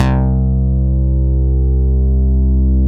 Index of /90_sSampleCDs/Roland L-CDX-01/BS _Synth Bass 1/BS _MIDI Bass